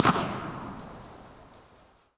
Concrete, asphalt, sand.
Download this impulse response (right click and “save as”)
PortageCreekTunnel.wav